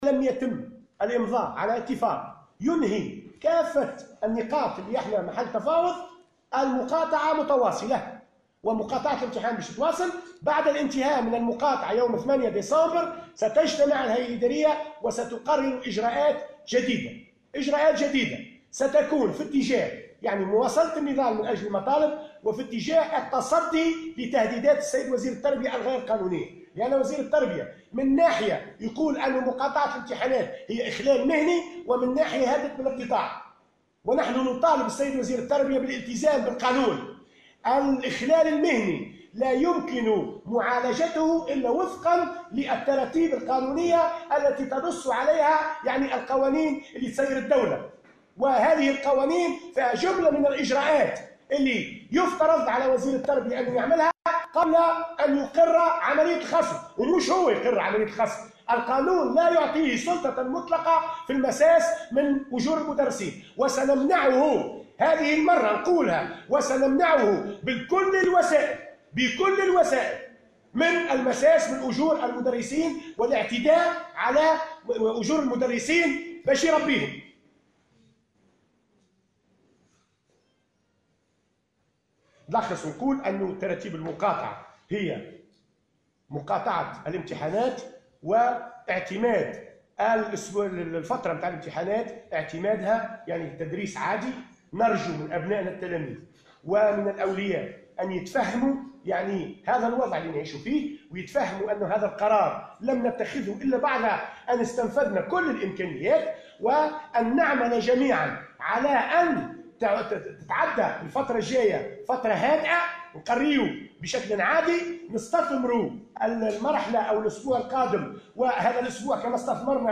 على هامش ندوة صحفية للجامعة العامة للتعليم الثانوي